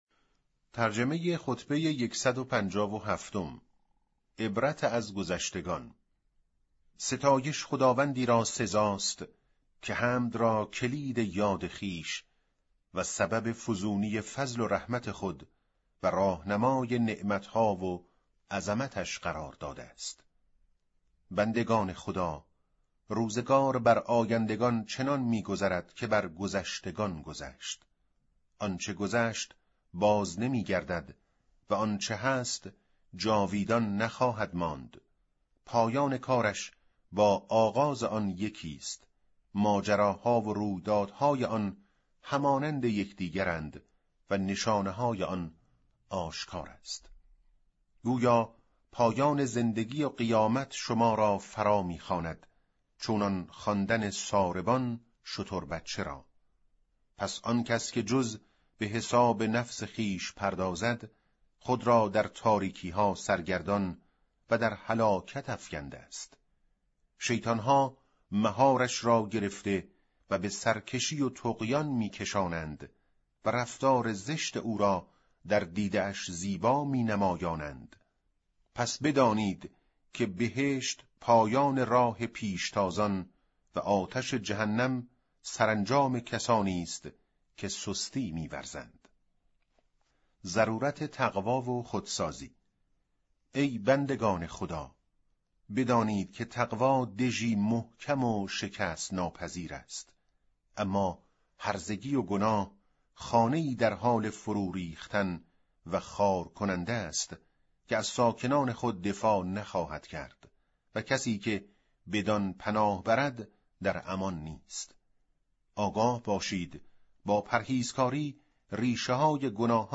به گزارش وب گردی خبرگزاری صداوسیما؛ در این مطلب وب گردی قصد داریم، خطبه شماره ۱۵۷ از کتاب ارزشمند نهج البلاغه با ترجمه محمد دشتی را مرور نماییم، ضمنا صوت خوانش خطبه و ترجمه آن ضمیمه شده است: